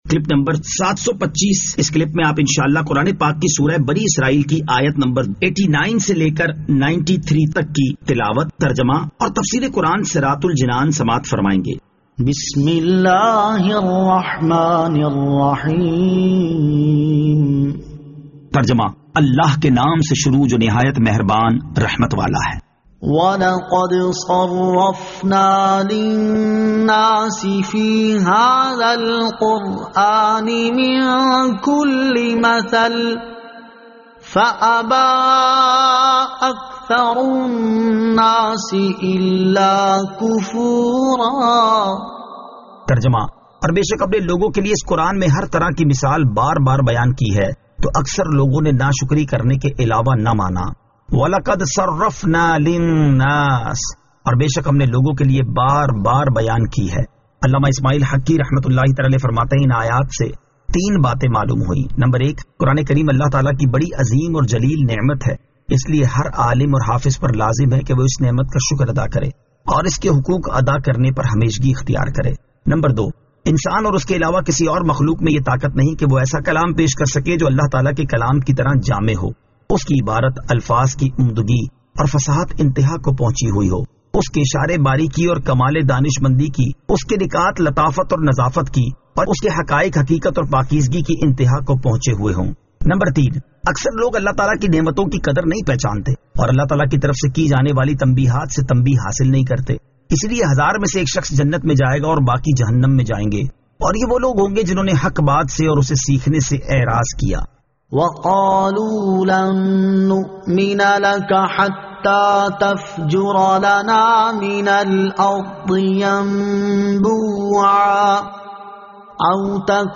Surah Al-Isra Ayat 89 To 93 Tilawat , Tarjama , Tafseer